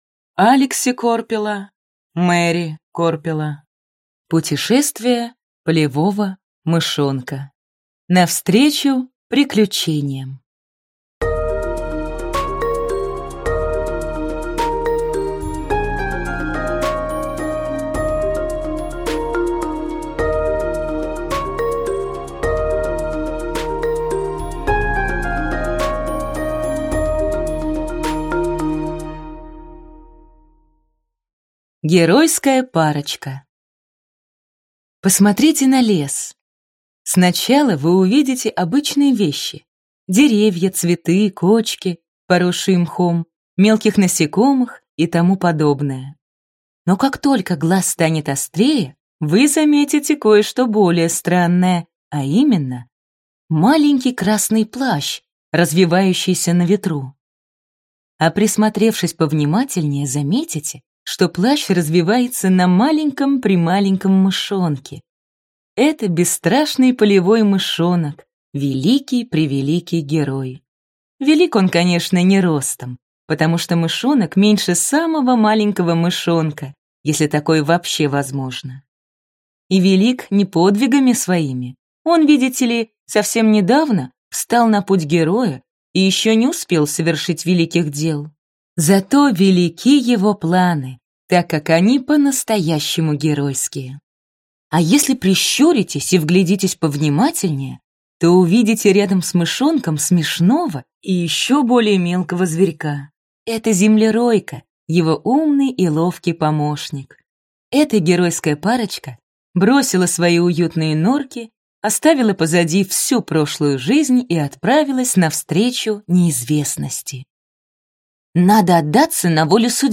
Аудиокнига Путешествие полевого мышонка. Навстречу приключениям | Библиотека аудиокниг